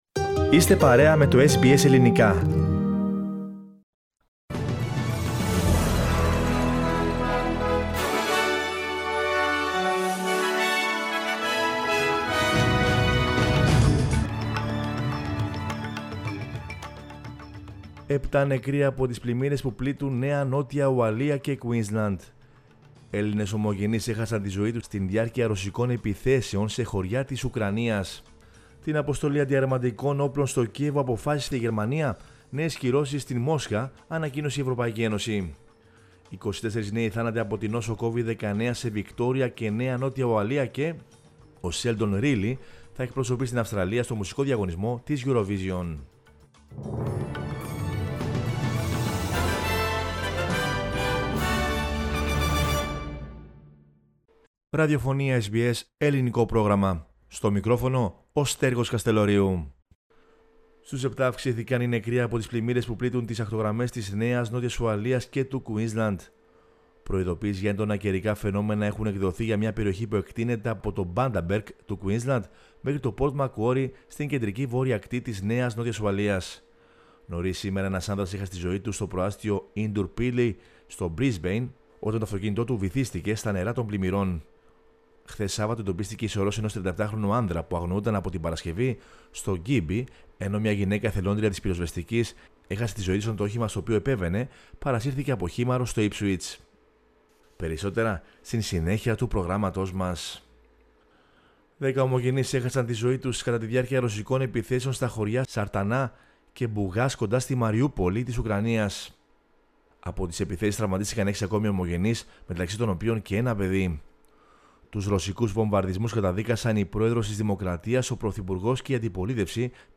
News in Greek from Australia, Greece, Cyprus and the world is the news bulletin of Sunday 27 February 2022.